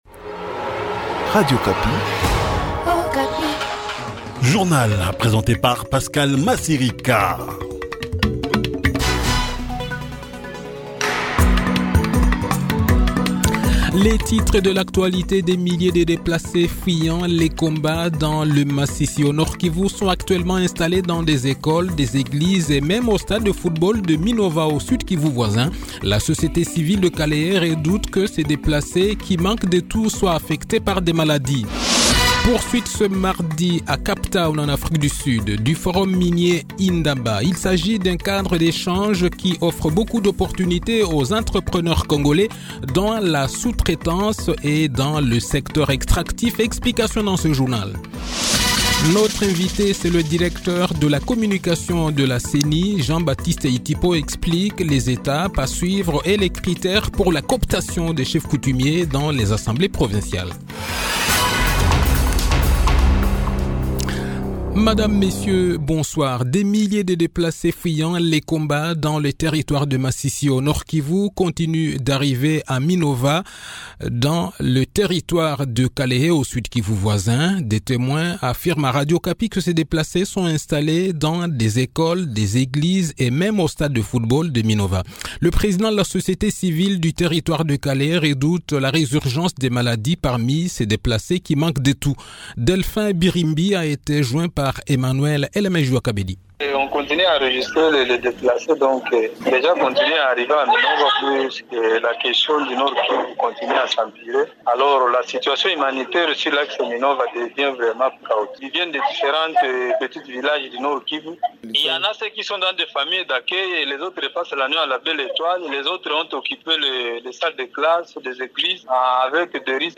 Le journal de 18 h, 6 février 2024